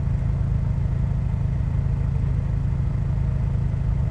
rr3-assets/files/.depot/audio/Vehicles/i4_01/i4_01_idle.wav
i4_01_idle.wav